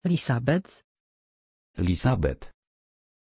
Aussprache von Lisabet
pronunciation-lisabet-pl.mp3